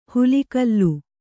♪ hulikallu